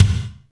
31.04 KICK.wav